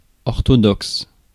Ääntäminen
Ääntäminen France: IPA: /ɔʁ.tɔ.dɔks/ Haettu sana löytyi näillä lähdekielillä: ranska Käännös Konteksti Ääninäyte Adjektiivit 1. orthodox uskonto 2. mainstream US 3. mainline 4.